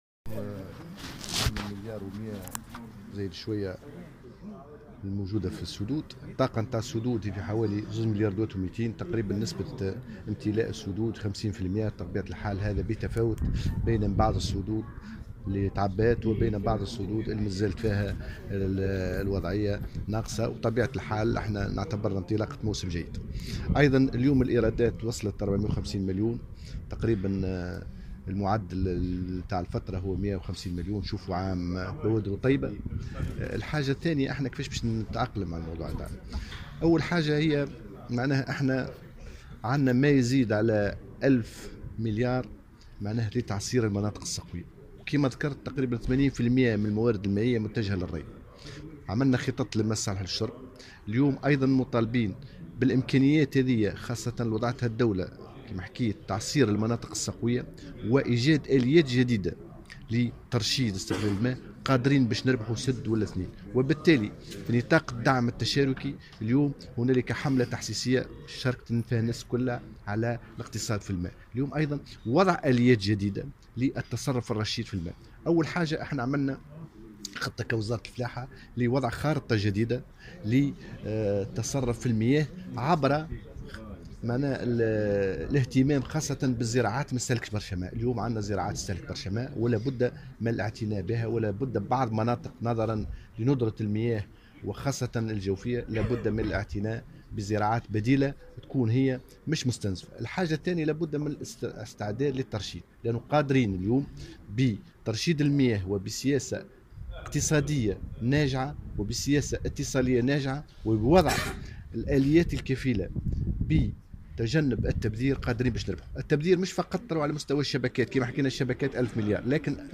وقال في تصريح لمراسل "الجوهرة أف أم" على هامش ندوة حول أزمة الموارد المائية بمقر الاتحاد التونسي للفلاحة والصيد البحري، أن معدل امتلاء السدود بلغ نحو 50 % وأوضح في ذات الصدد أن الايرادات بلغت 450 مليون متر مكعب مقابل معدلات سابقة بنحو 150 مليون متر مكعب.